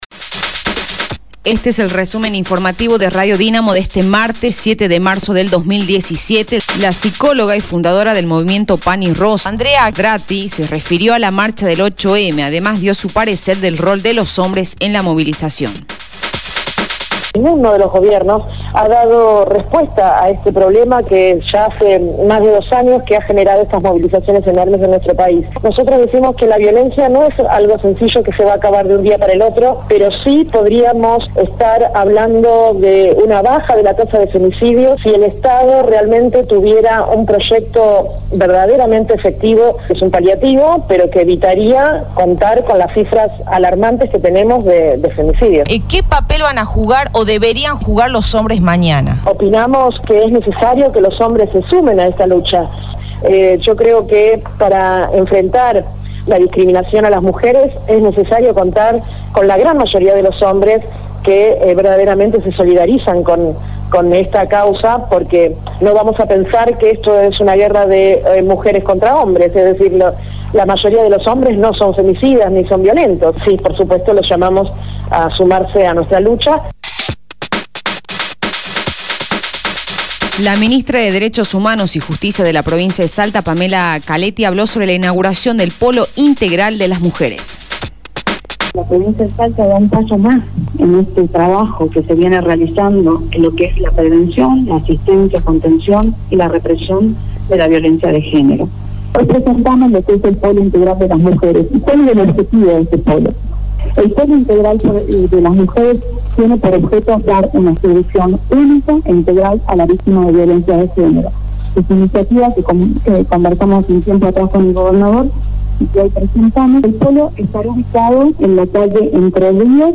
Resumen Informativo de Radio Dinamo del día 07/03/2017 2° Edición